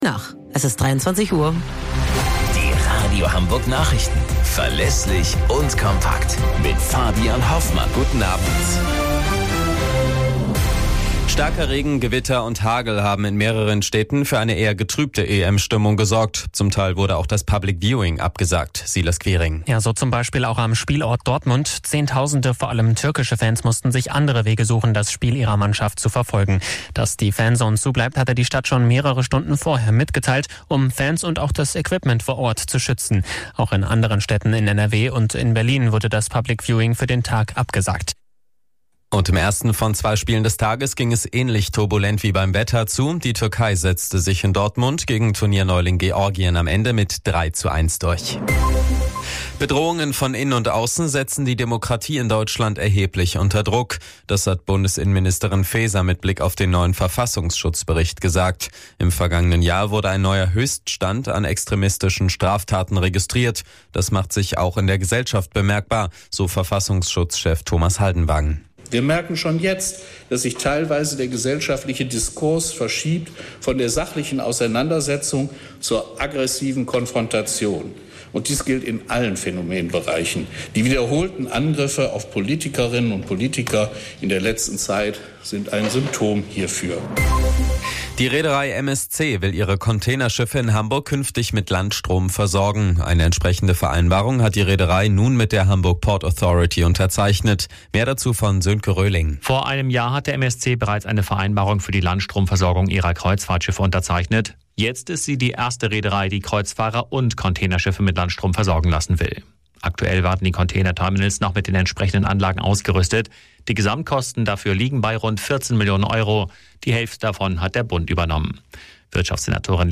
Radio Hamburg Nachrichten vom 19.06.2024 um 01 Uhr - 19.06.2024